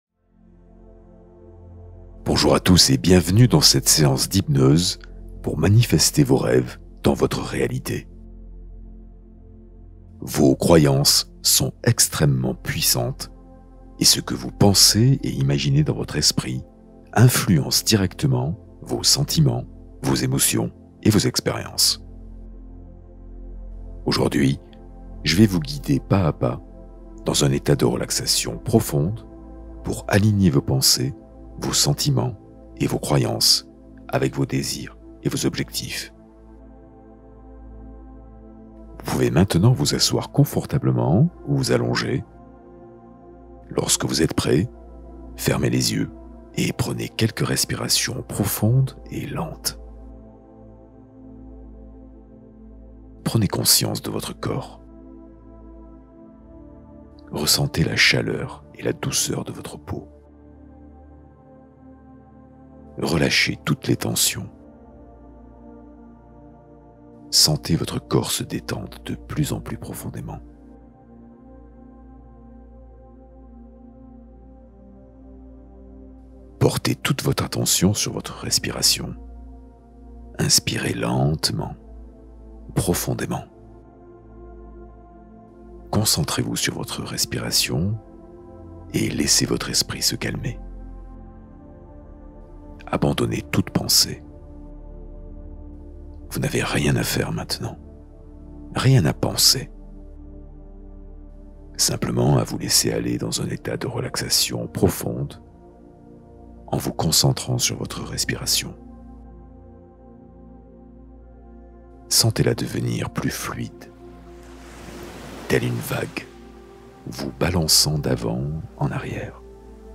Fréquence 1111 Hz : cohérence émotionnelle et régénération intérieure